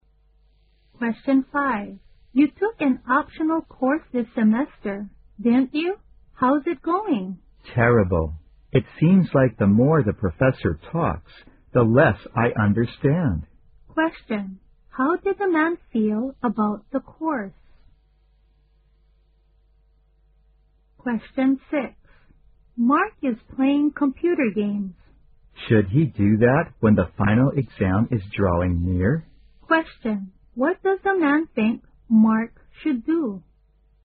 在线英语听力室111的听力文件下载,英语四级听力-短对话-在线英语听力室